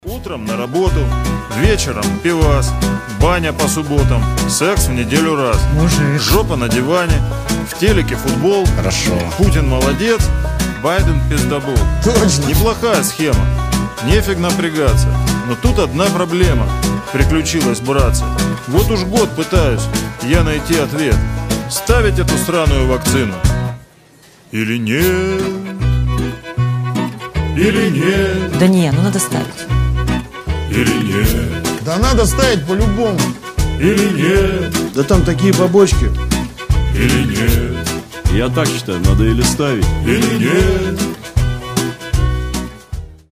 • Качество: 320, Stereo
гитара
скрипка
банджо